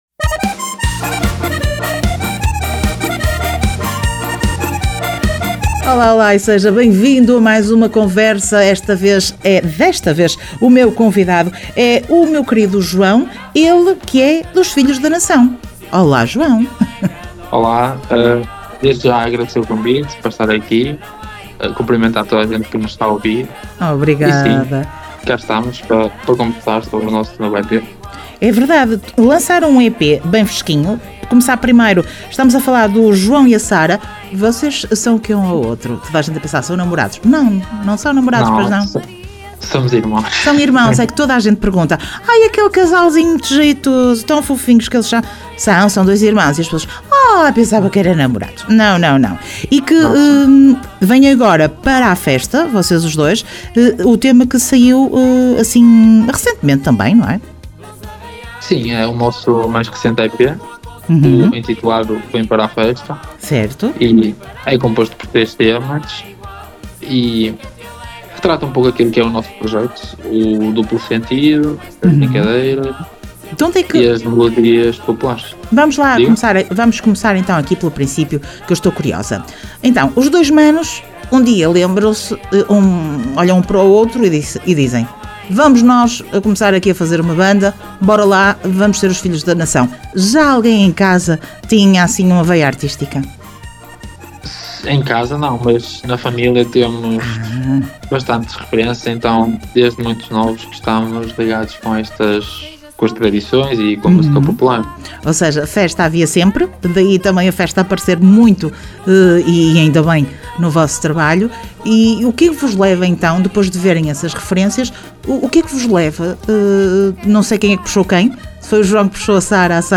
Entrevista Filhos da Nação